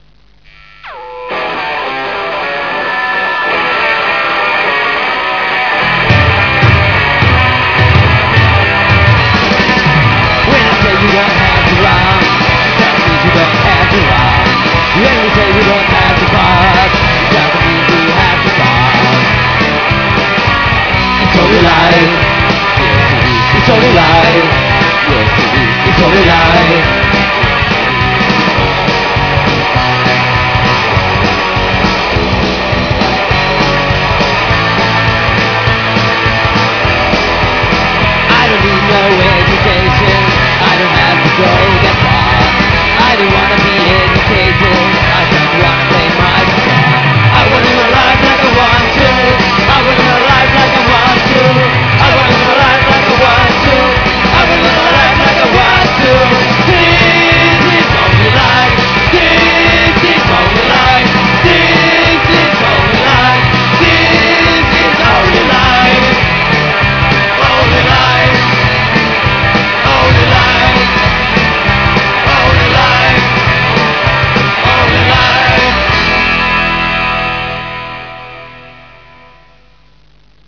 Heath played heavy rock.
DRUMS
BASS
GUITAR & VOCALS